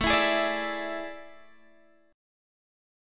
Listen to AM7sus4 strummed